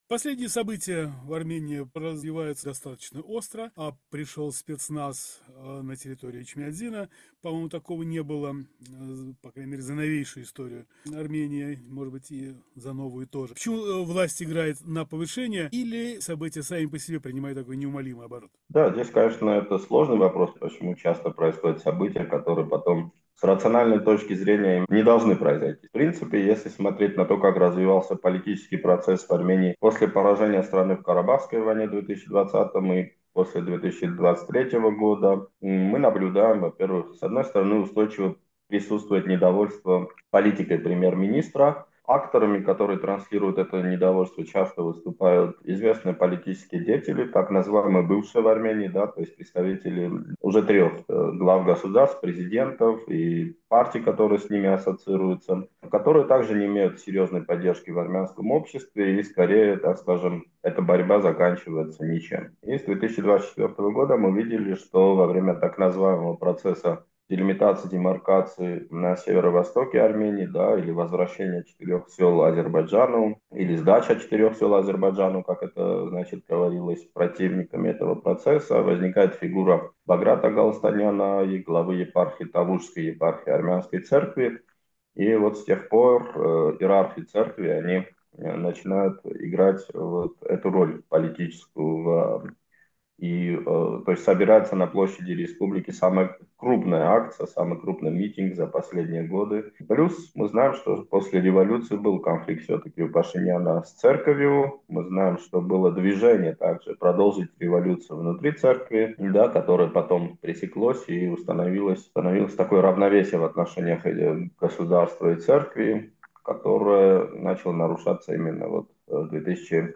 Рубрика "Гость недели", беседы с политиками, экспертами, общественными деятелями